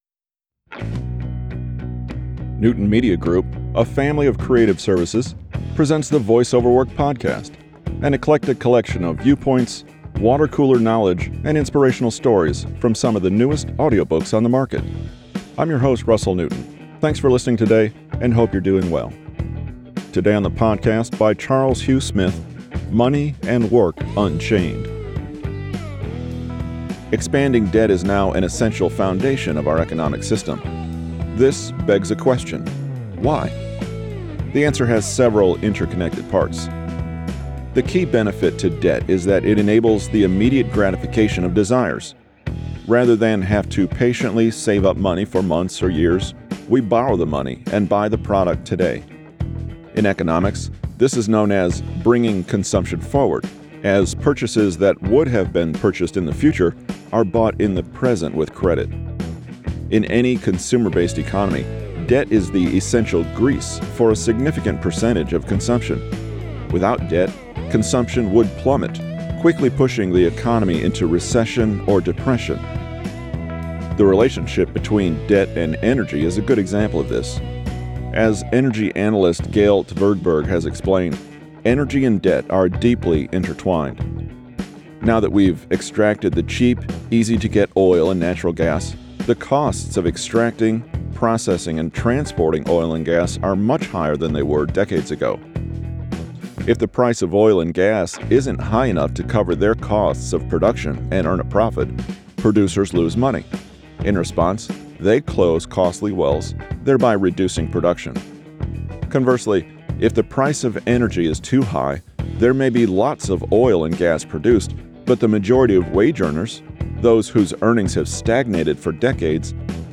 Debt and energy costs serve as a model for understanding larger economic theories. Charles Hugh Smith from his recent book Money and Work Unchained, available now as an audiobook.